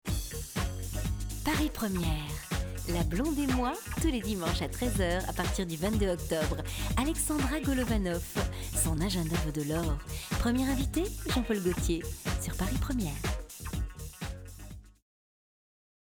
Naturelle